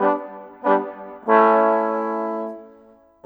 Rock-Pop 06 Brass 03.wav